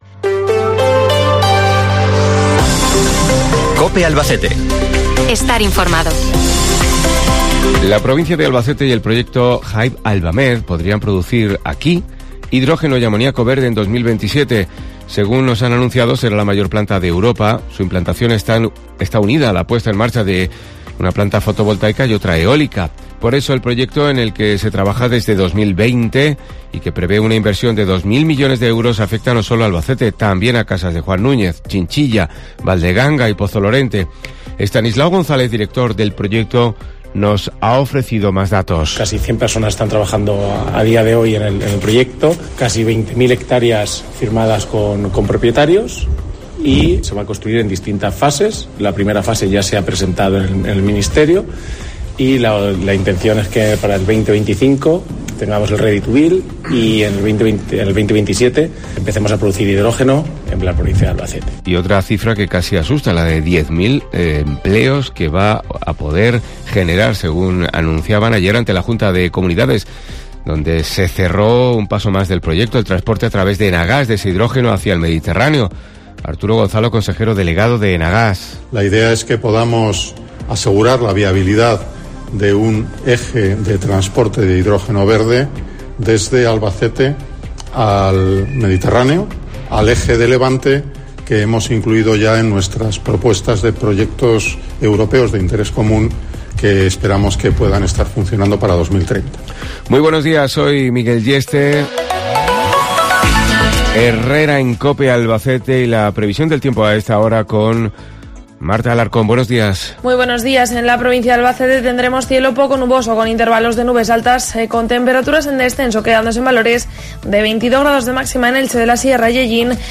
El proyecto Hive Albamed de producción de hidrógeno verde en Albacete abre este informativo